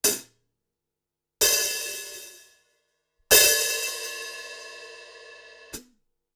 Échantillons sonores Audio Technica AE-5100
Audio Technica AE5100 - Hi Hats